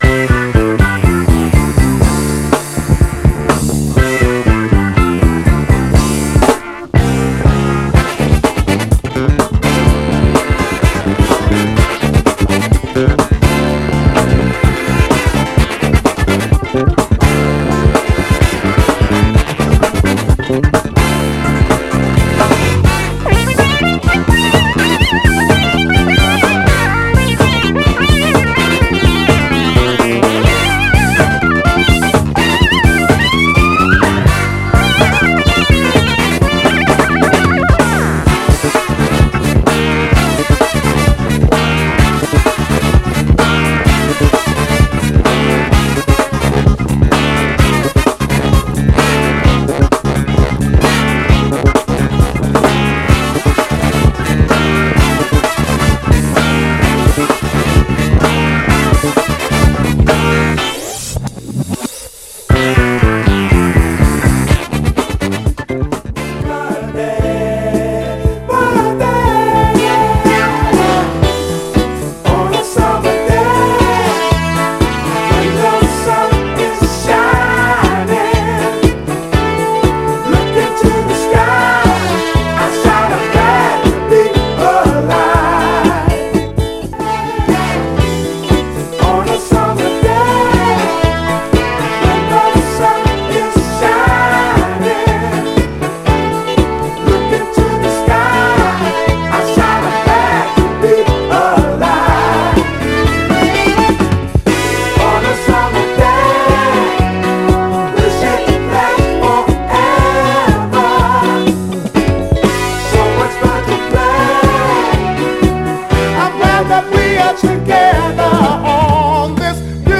徐々に高揚していく抑揚洗練グルーヴがフロアで輝く
ベイエリア・ファンクっぽいキレのあるブラス隊と尖ったシンセが効いたセミ・インスト・ファンク
※試聴音源は実際にお送りする商品から録音したものです※